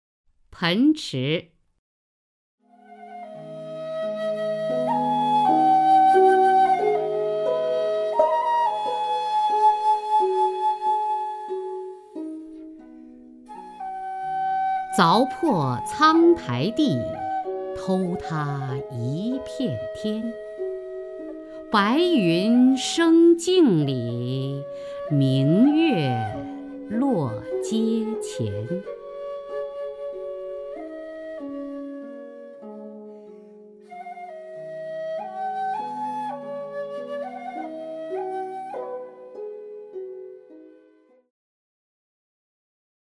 雅坤朗诵：《盆池》(（唐）杜牧) （唐）杜牧 名家朗诵欣赏雅坤 语文PLUS